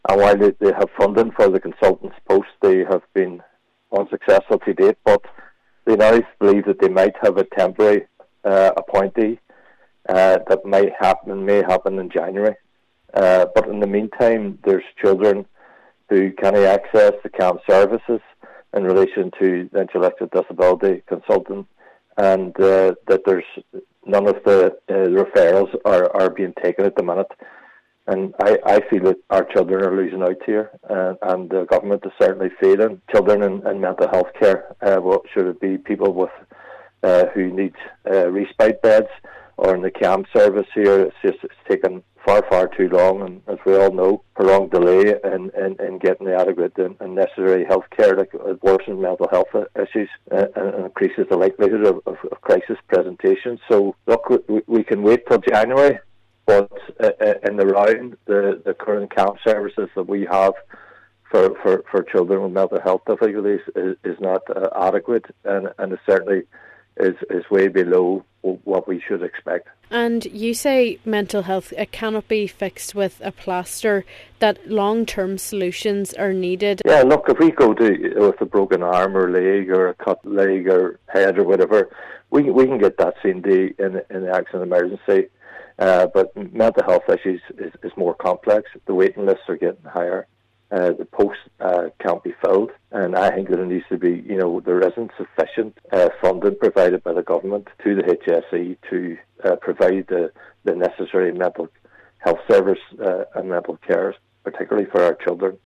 Cllr McMonagle says there is a need for serious reforms of governance and management of children’s mental health services in Donegal: